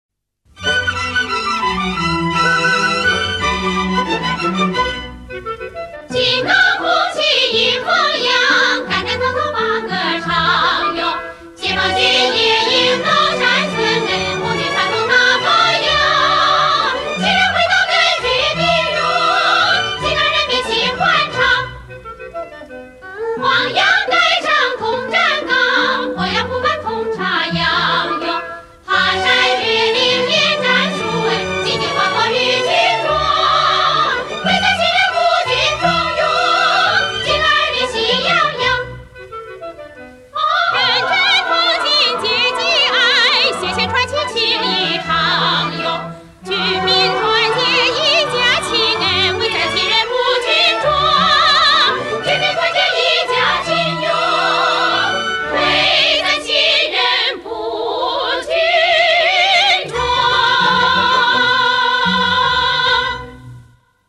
充满朝气与活力！ 1972年原唱版